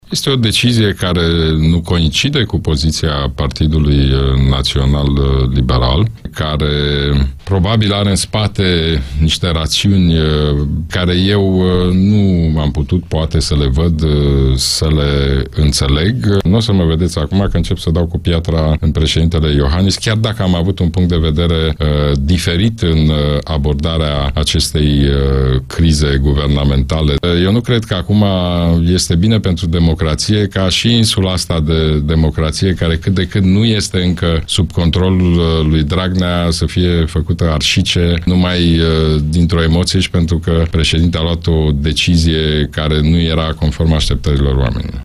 Liderului PNL, Ludovic Orban invitat la interviurile Europa Fm, a susținut că nu înţelege decizia preşedintelui Klaus Iohannis de a o desemna pe Viorica Dăncilă pentru funcţia de prim-ministru.